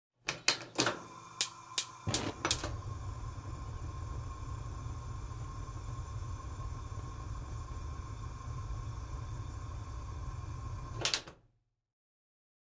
lighter-sound